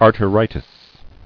[ar·te·ri·tis]